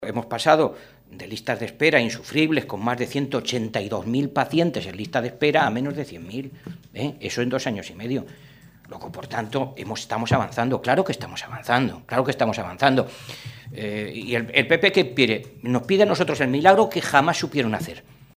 El diputado del Grupo socialista, Fernando Mora, ha señalado hoy que, por muchos balances en negativo que hagan desde el PP, todo el mundo tiene claro que “en materia sanitaria estamos avanzando y se está haciendo un gran esfuerzo por revertir todos los recortes que en esta y otras áreas llevó a cabo el ejecutivo de Cospedal”.
Cortes de audio de la rueda de prensa